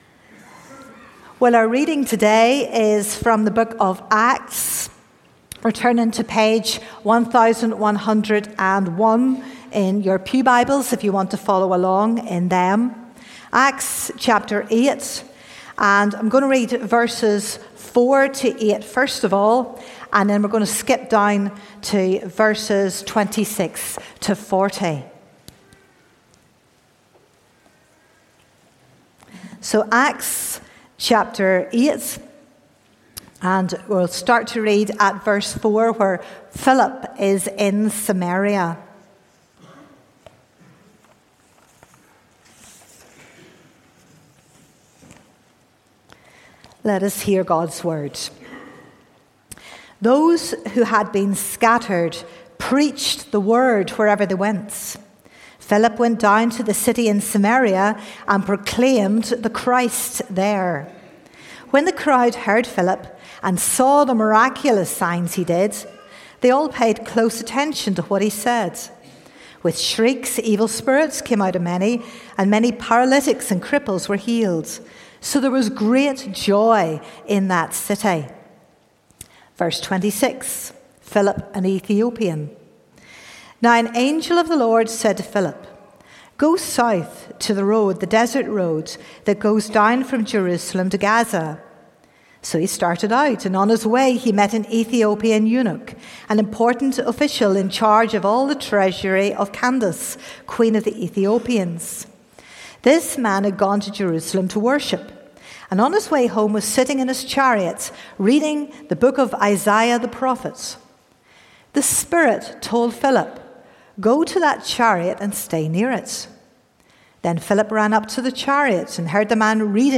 Sermons - High Kirk Presbyterian Church